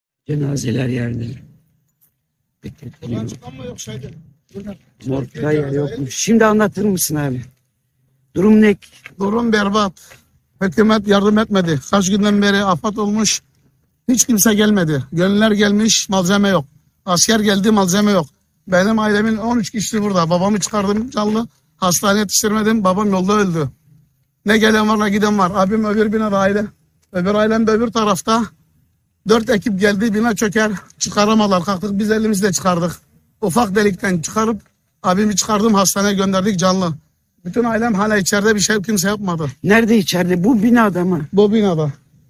Deprem mağdurlarının sosyal medyada paylaştığı videoları tarayıp ses dosyalarına dönüştürerek internet sitesine yükledik.